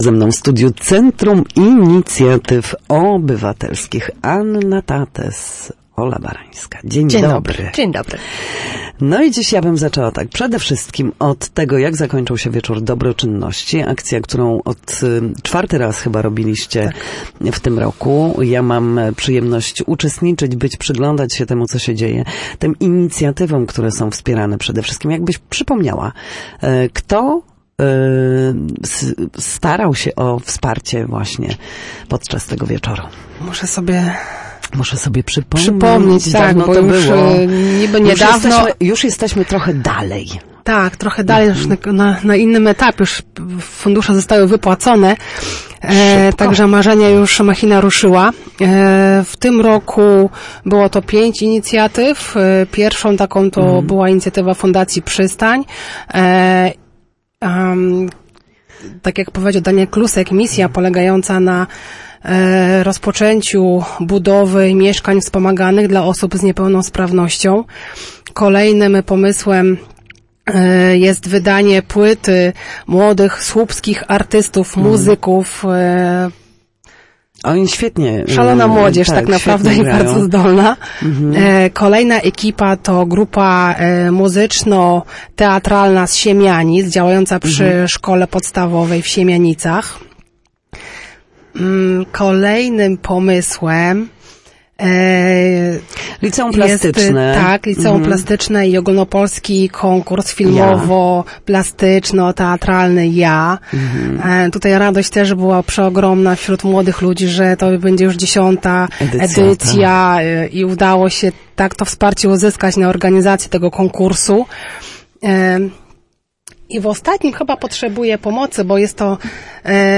W Studiu Słupsk